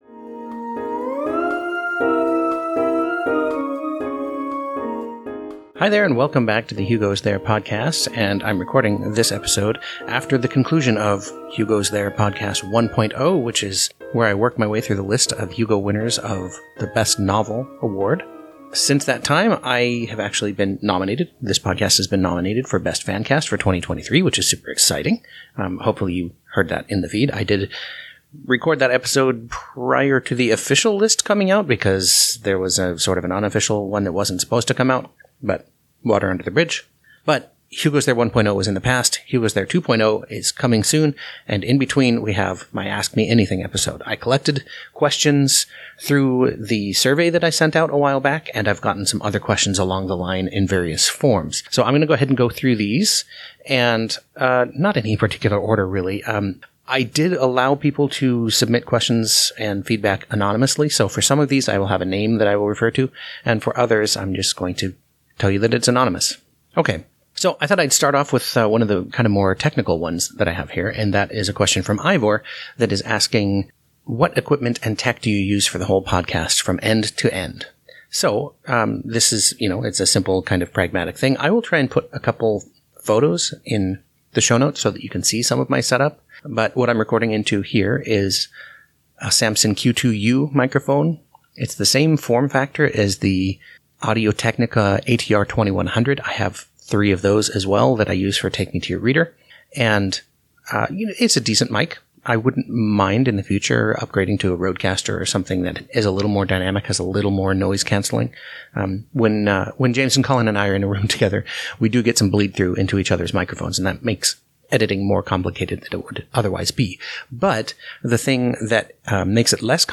I collected questions thru my Hugos There Future Survey, and this is the episode in which I answer them! My recording setup: Samson Q2U or ATR2100 (both pictured below) Zoom Podtrac P4 (pictured be…